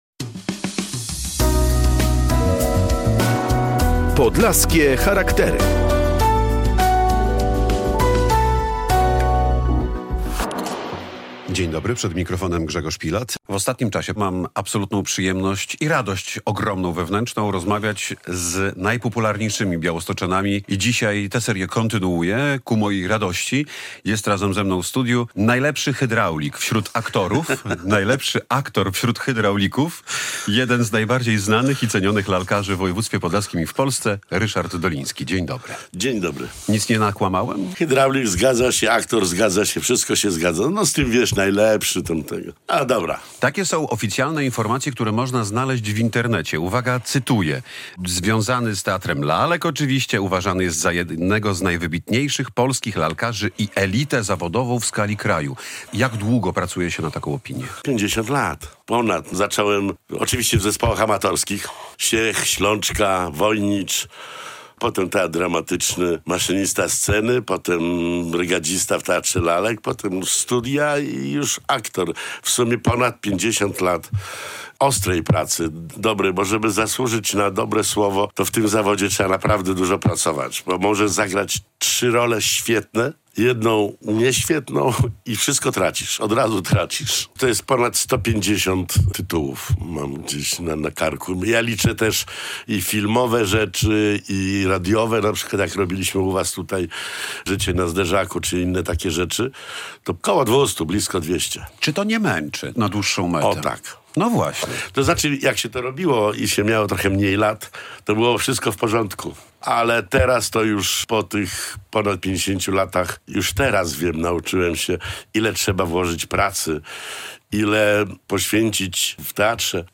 Zapraszamy w podróż przez pół wieku historii Białostockiego Teatru Lalek. Gościem Podlaskich Charakterów będzie aktor, reżyser, legenda sceny